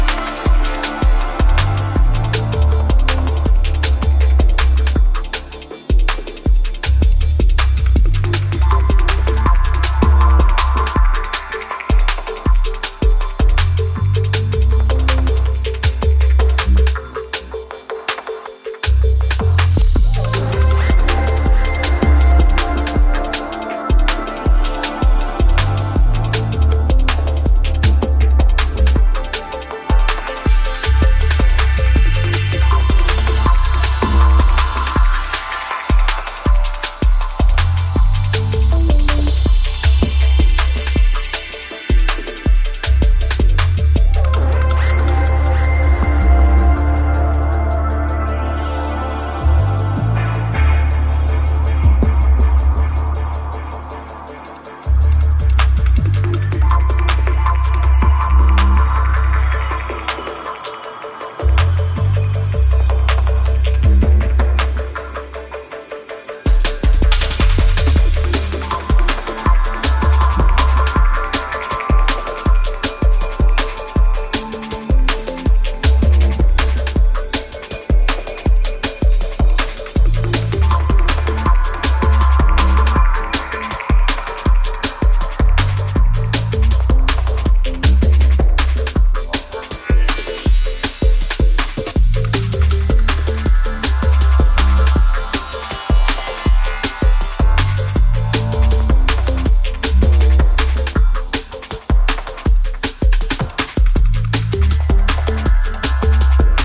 mind-bending DnB remix